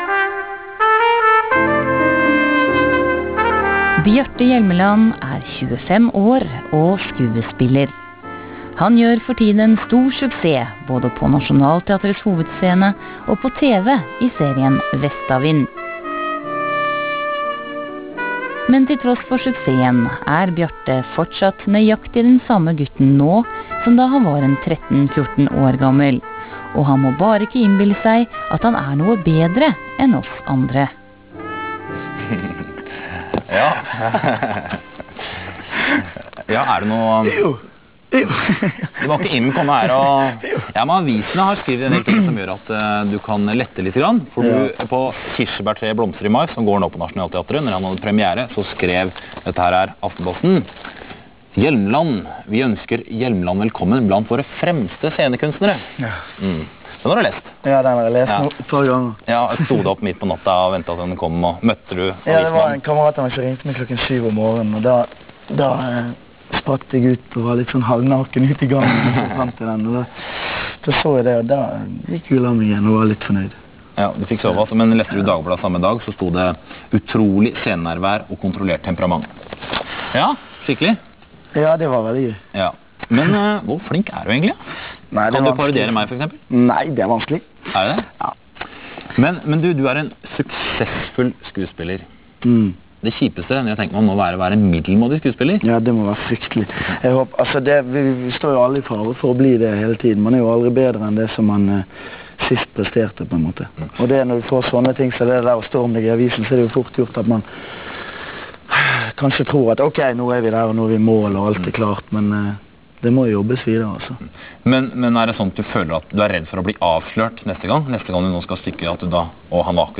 Lille Lørdag fjernsynsradio: Intervju med Bjarte Hjelmeland fra sendingen (.au lydfil, 2,2MB)